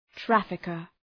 Προφορά
{‘træfıkər}